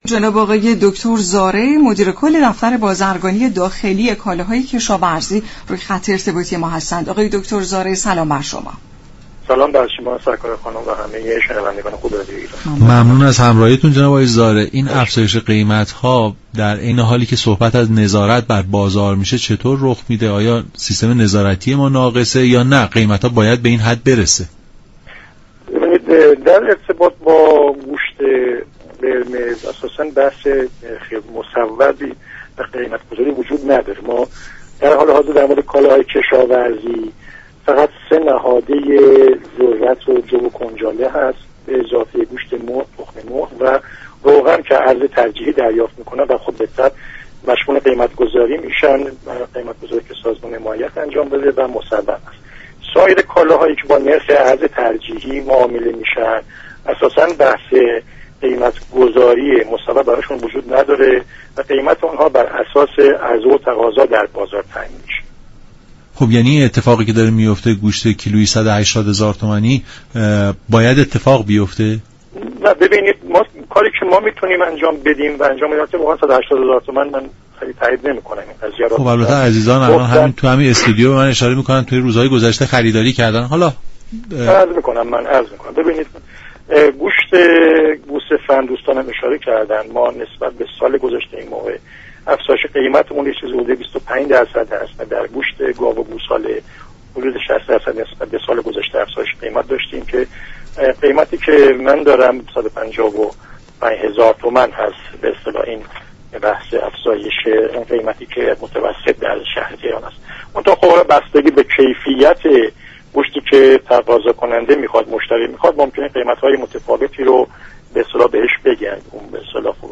به گزارش شبكه رادیویی ایران، ابراهیم زارع، مدیر كل دفتر بازرگانی داخلی كالاهای كشاورزی معاونت توسعه بازرگانی وزارت جهاد كشاورزی در برنامه نمودار درباره گرانی ها و افزایش قیمت كالاها گفت: برای گوشت قرمز اساسا هیچگونه قیمت گذاری وجود ندارد و تنها كالاهایی نظیر ذرت، جو ،كنجاله، گوشت مرغ، تخم مرغ و روغن كه ارز ترجیحی دریافت می كنند، شامل قیمت گذاری سازمان حمایت می شوند.
برنامه نمودار شنبه تا چهارشنبه هر هفته ساعت 10:20 از رادیو ایران پخش می شود.